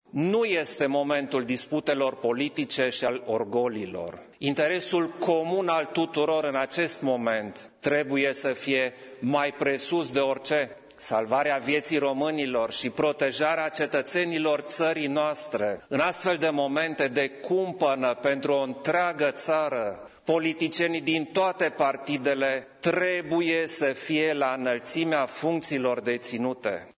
Klaus Iohannis a subliniat că prioritatea, în contextul pandemiei, ar trebui să fie ‘salvarea vieţii românilor şi protejarea cetăţenilor ţării noastre’: